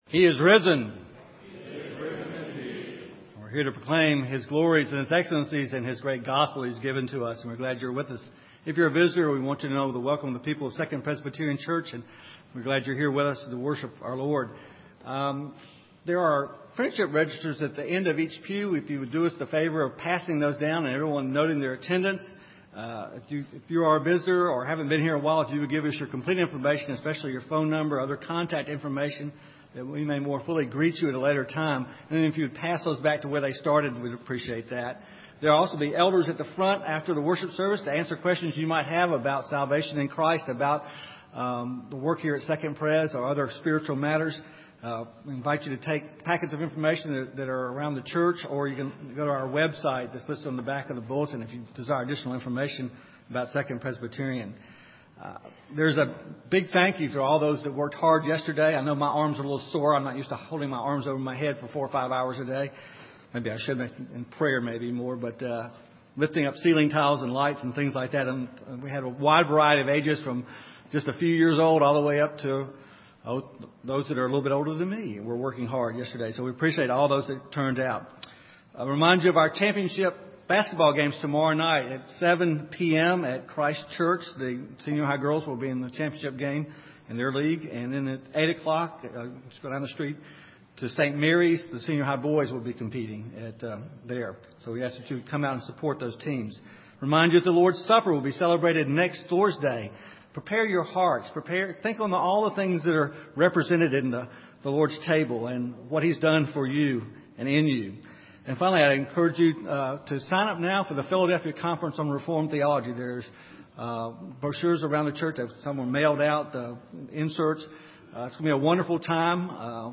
This is a sermon on John 11:17-26.